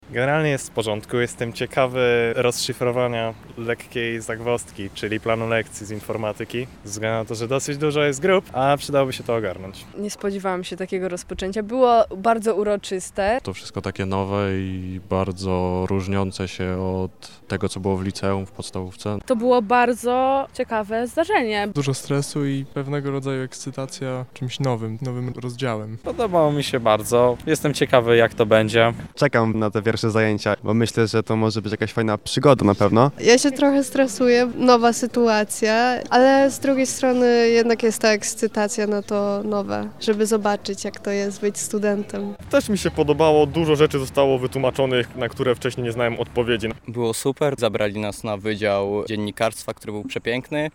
Zapytaliśmy pierwszoroczniaków o ich odczucia:
[SONDA] Rozpoczęcie roku akademickiego
studenci-sonda.mp3